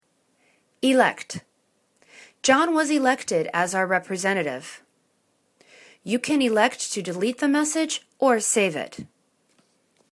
elect    /i'lekt/    [T]